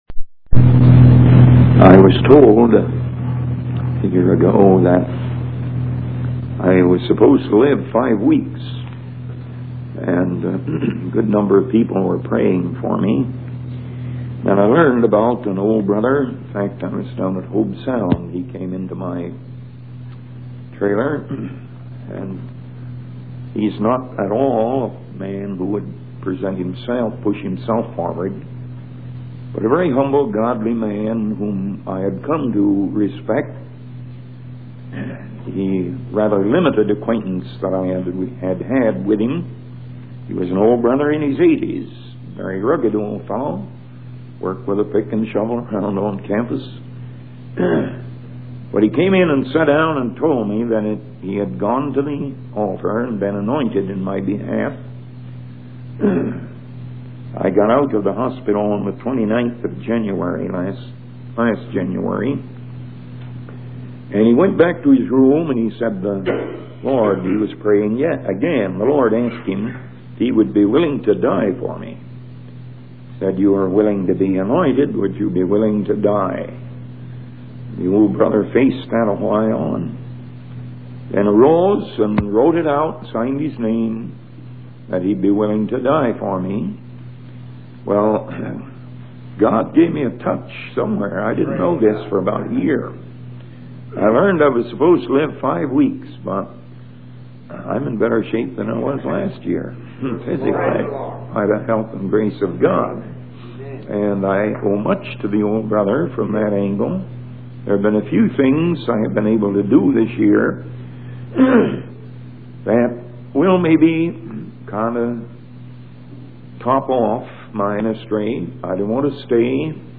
In this sermon, the preacher emphasizes the power and speed of God's spoken word.